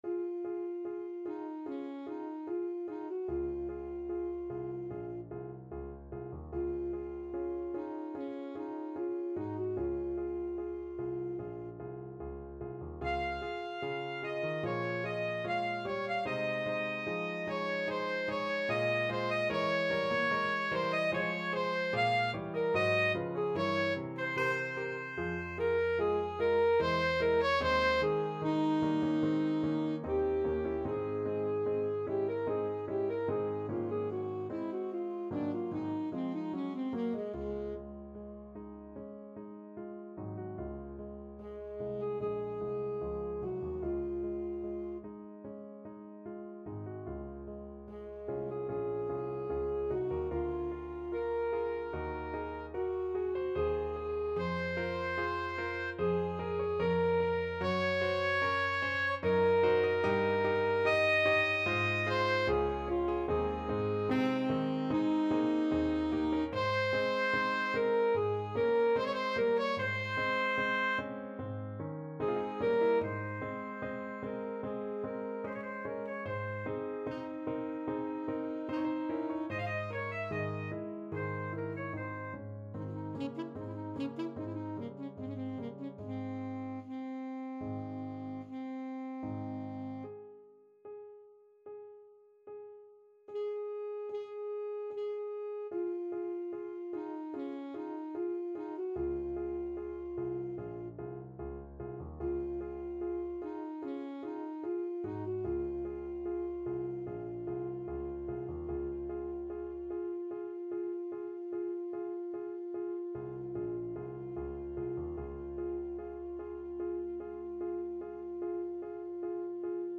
Alto Saxophone
~ = 74 Moderato
4/4 (View more 4/4 Music)
Classical (View more Classical Saxophone Music)